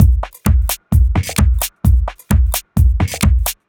Minimal Funk 03.wav